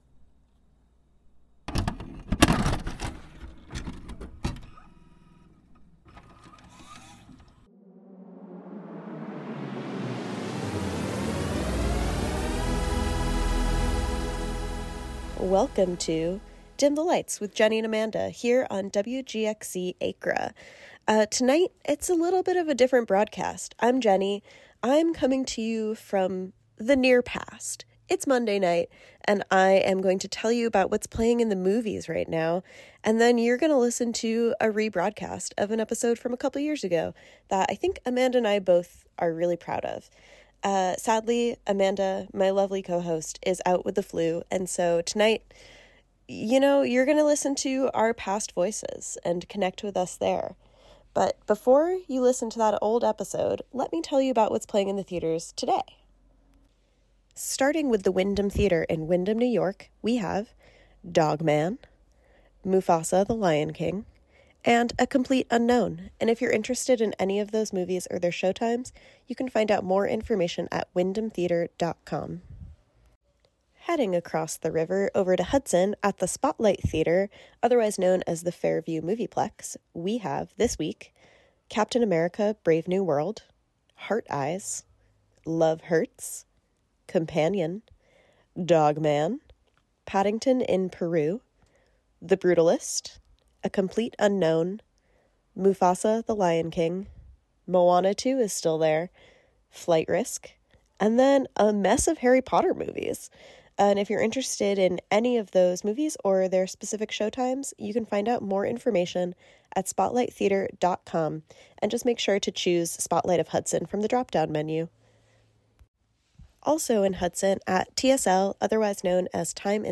Show begins with previews of local movie showings, which is also often heard the next day on the " WGXC Morning Show " or the " WGXC Afternoon Show ," or " All Together Now! " This is a rebroadcast of an episode from January 2023, with updated movie times for the week of February 10, 2025 Play In New Tab (audio/mpeg) Download (audio/mpeg)